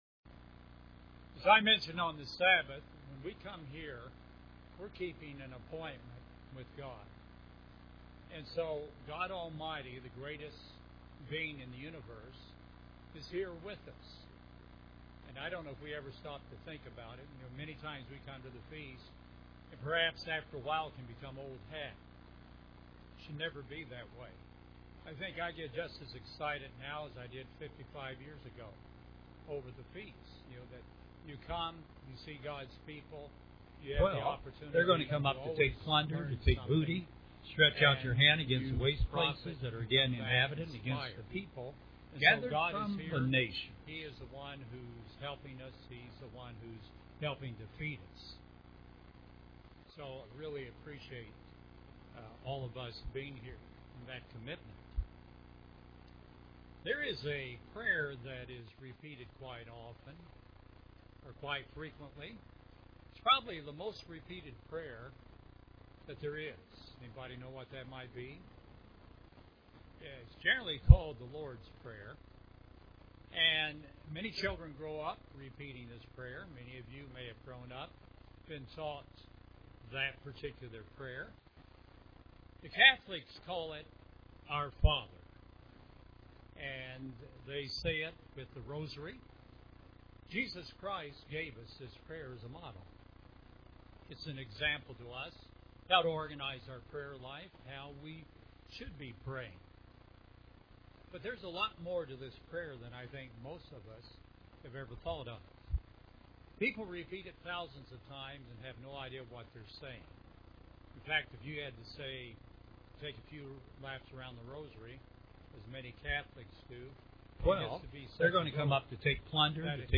This sermon was given at the Panama City Beach, Florida 2012 Feast site.